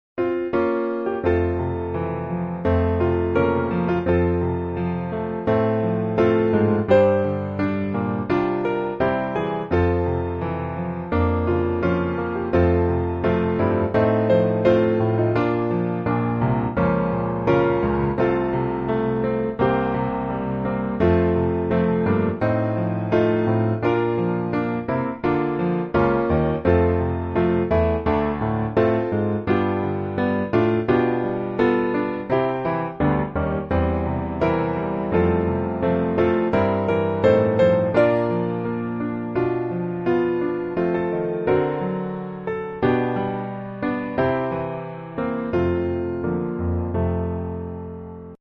F Major